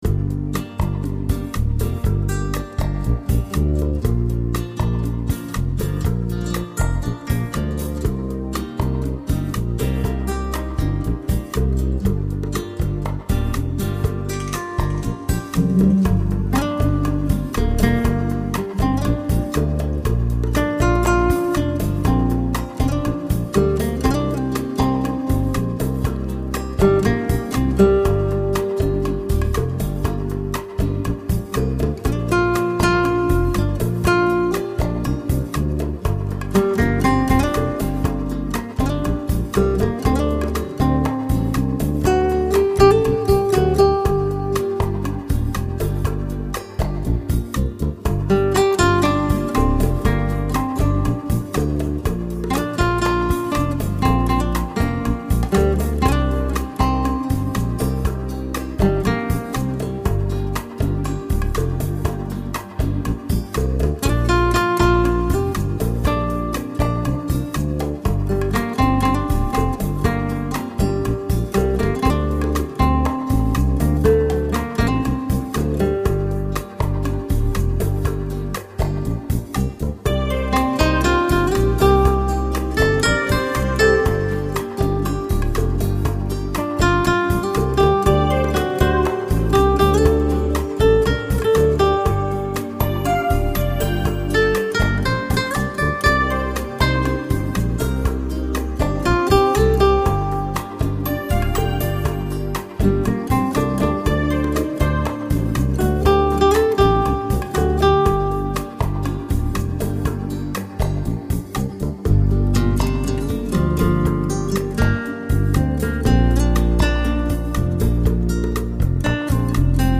但是吉他声音也录得通透、清澈、传真和富有空气感，把乐意表现得细致入微、丝丝入扣。